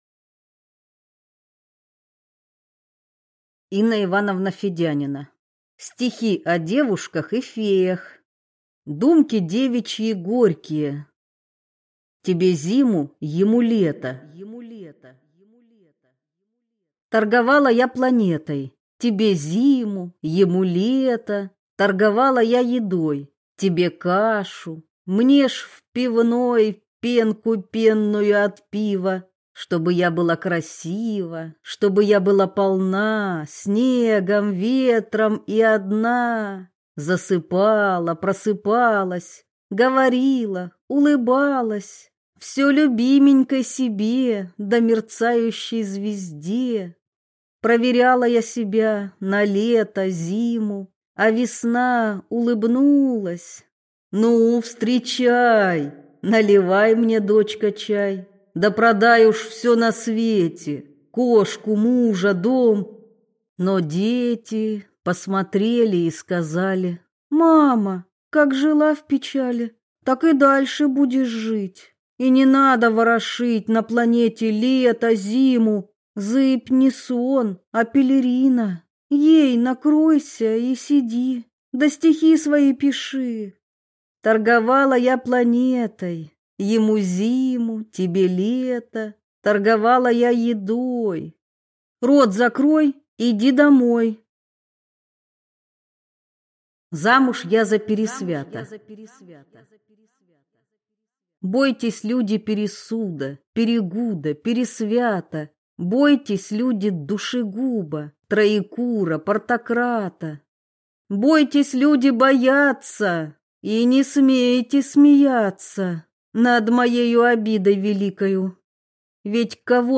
Аудиокнига Стихи о девушках и феях | Библиотека аудиокниг